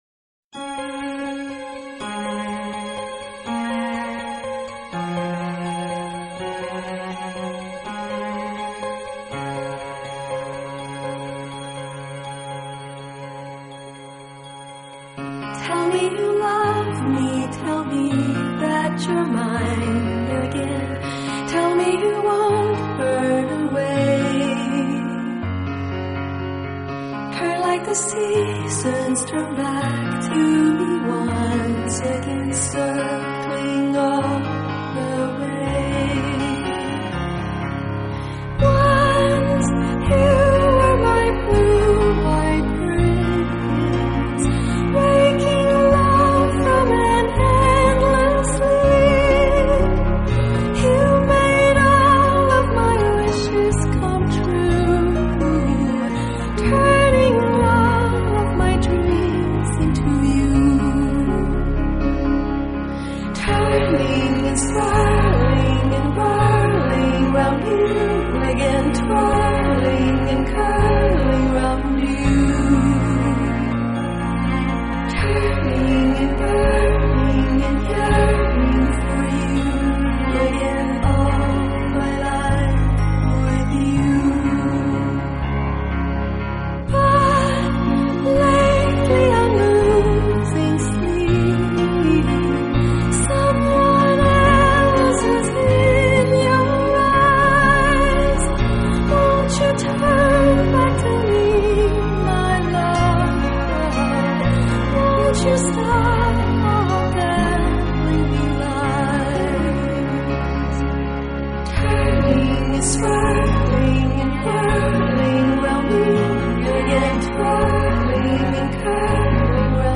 纯乐钢琴
还有亮丽装点整个音场的打击乐器，使空灵幽远的曲子，增添一份梦幻缤纷。”